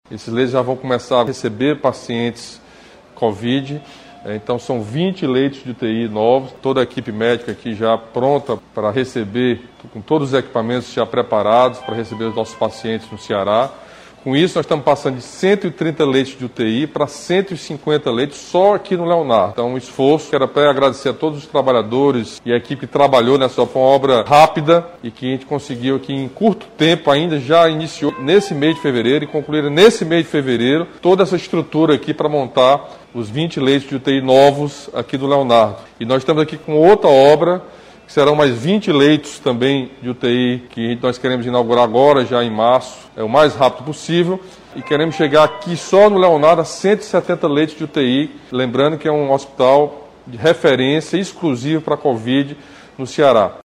O anúncio foi realizado nesta sexta-feira (26) durante transmissão ao vivo nas redes sociais.
A entrega amplia a capacidade de atendimento da unidade para pacientes com coronavírus. O HELV passa a contar, agora, com 150 leitos de UTI Covid-19, como destacou o governador Camilo Santana.